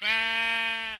Sound / Minecraft / mob / sheep / say3.ogg